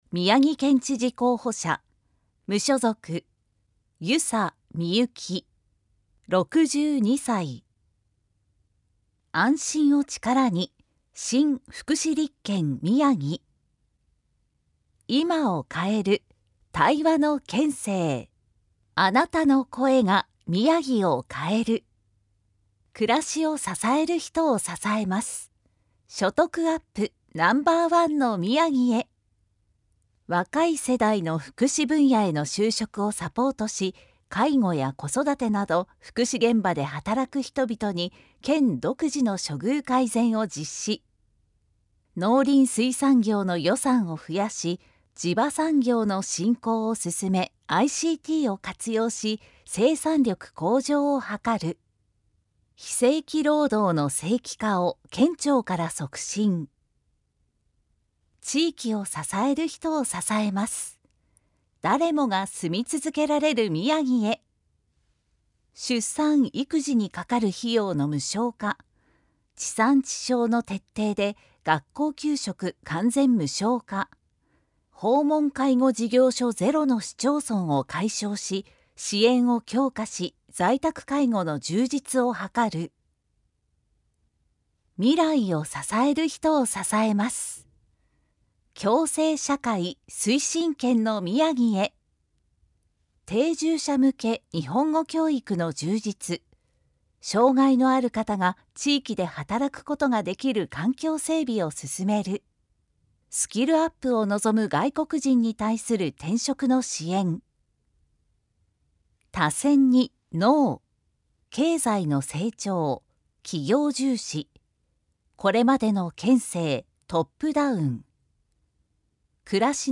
宮城県知事選挙候補者情報（選挙公報）（音声読み上げ用）